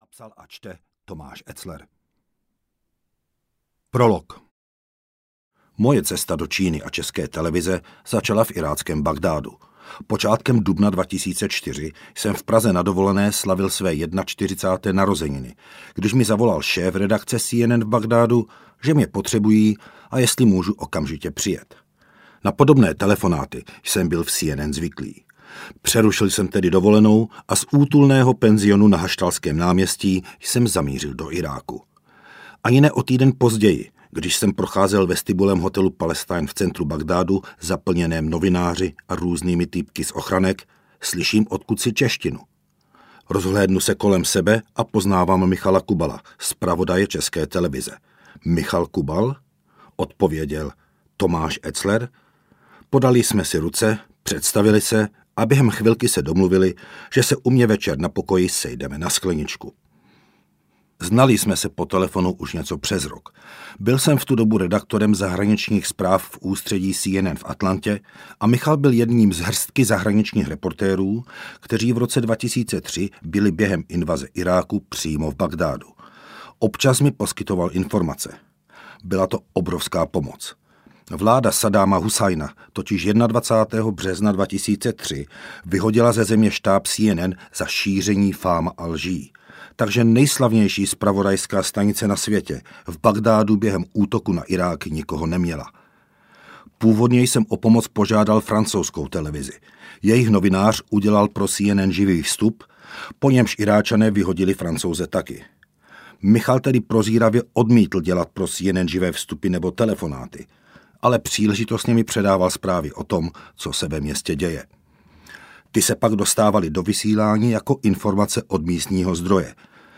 Novinářem v Číně audiokniha
Ukázka z knihy
• InterpretTomáš Etzler